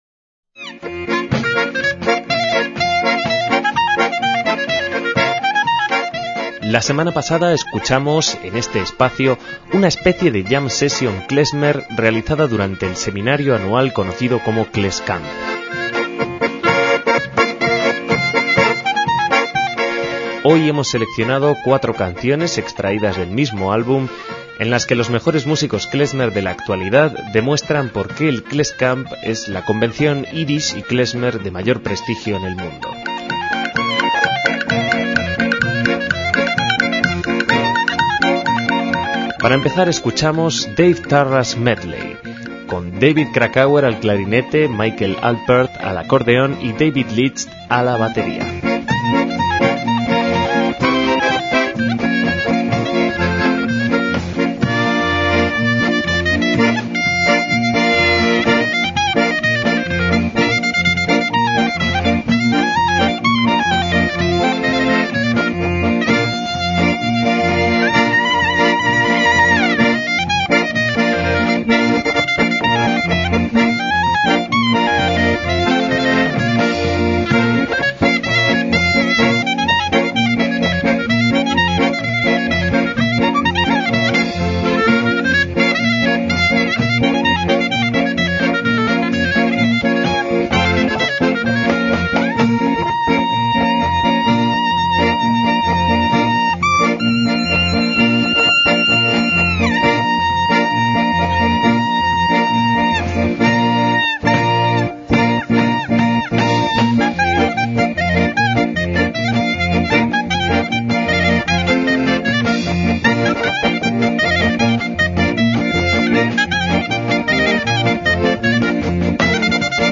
MÚSICA KLEZMER
Hoy os traemos algunos de los temas grabados por los grandes músicos estadounidenses del género en dichos encuentros y editados en un doble CD en 2004.